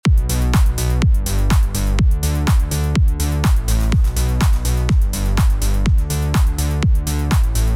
3. Straight 1/8th
This works particularly well when coupled with sidechain compression from the kick drum.